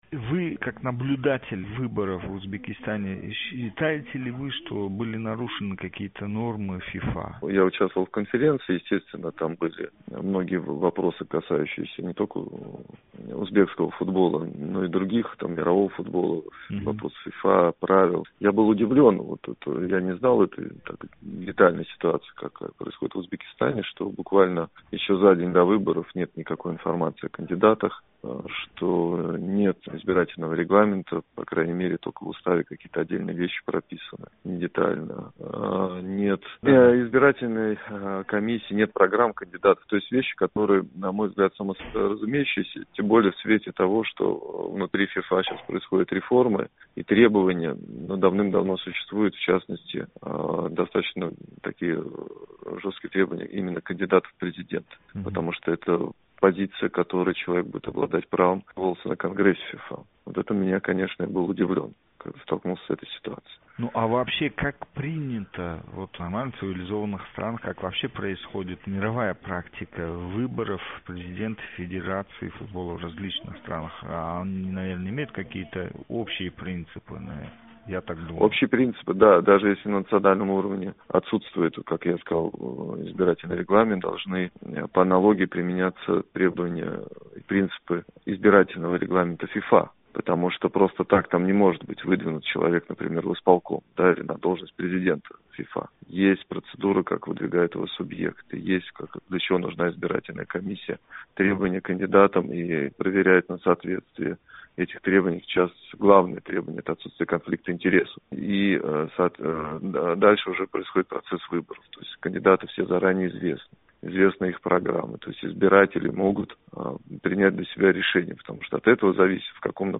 тўлиқ суҳбат